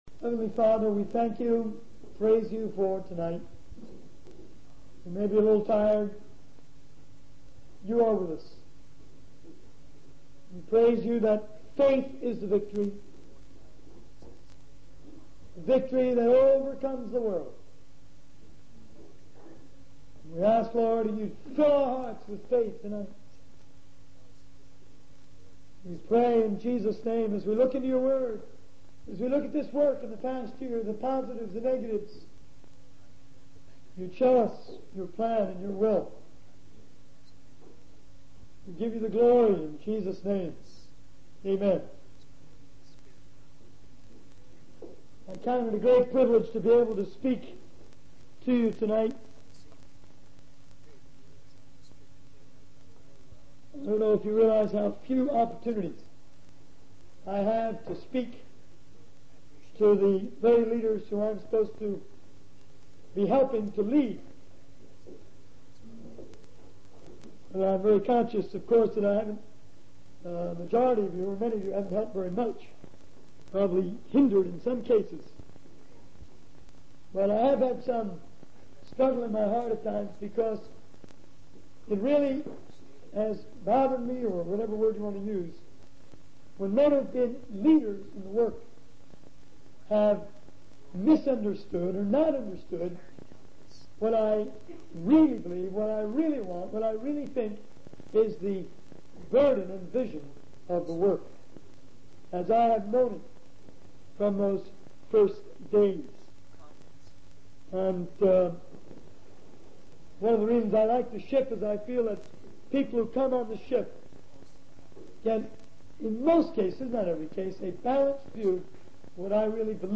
In this sermon, the speaker emphasizes the importance of organization in the complicated 20th century.